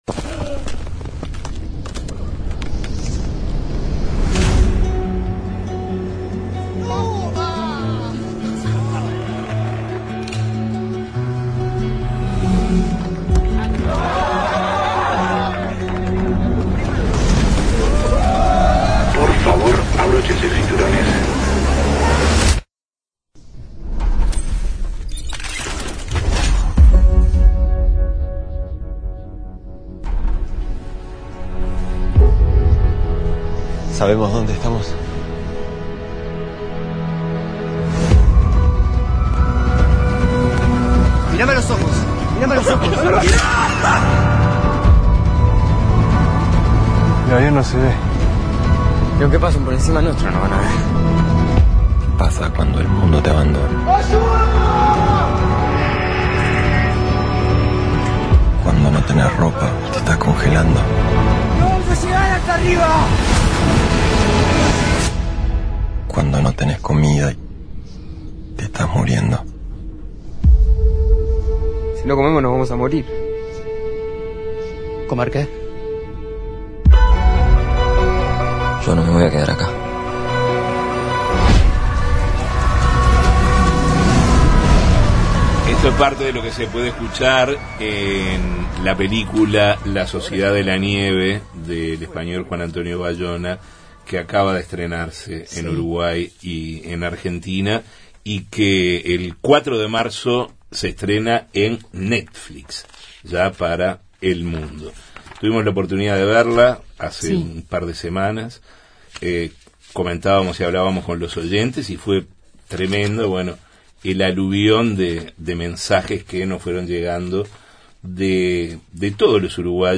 A 51 años del rescate de los supervivientes del accidente aéreo de los Andes ocurrido el 13 de octubre de 1972, en Justos y pecadores hablamos con Gustavo Zerbino, uno de los 16 supervivientes, a propósito del estreno de la película La sociedad de la nieve, una producción de Netflix, dirigida por el español Juan Andrés Bayona, y basada en el libro del mismo nombre, de Pablo Vierci.